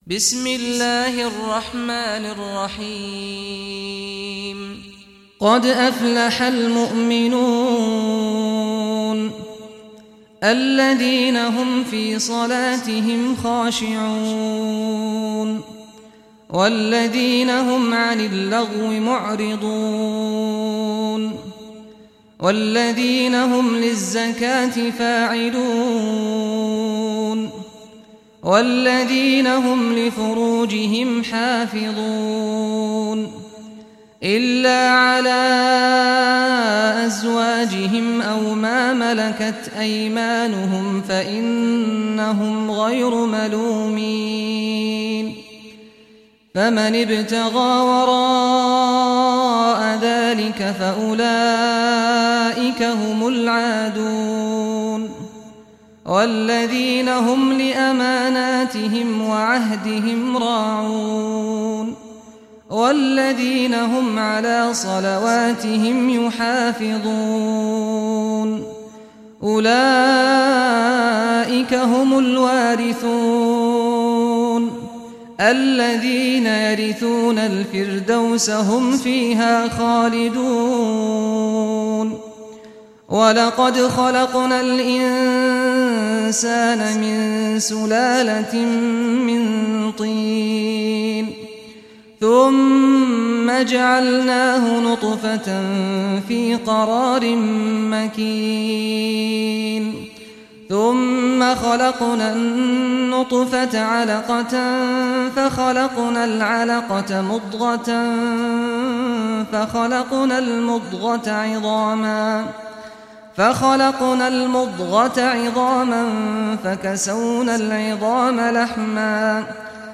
Surah Muminun Recitation by Sheikh Saad al Ghamdi
Surah Muminun, listen online mp3 tilawat / recitation in Arabic in the beautiful voice of Imam Sheikh Saad al Ghamdi.